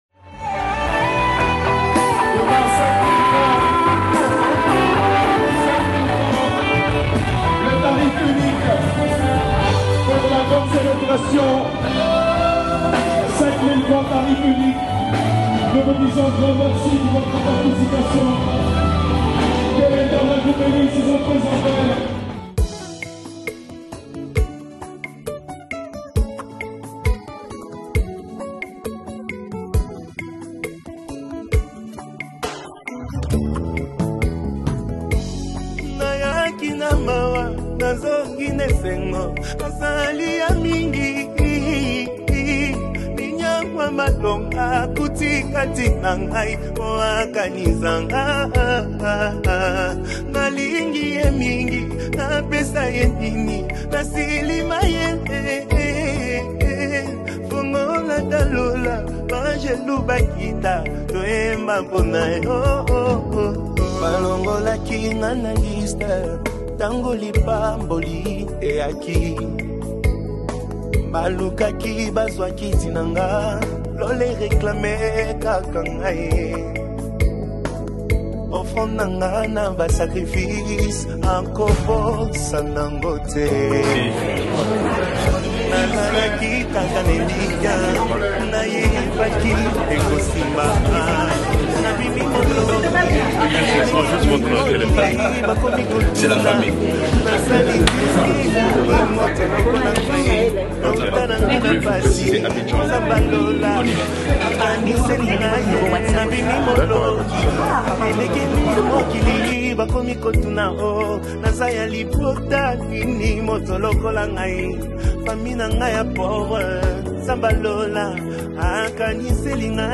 Congo Gospel Music
📍 VENUE: PALAIS DE LA CULTURE, ABIDJAN – CÔTE D’IVOIRE